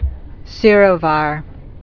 (sîrō-vär, -vâr, sĕr-)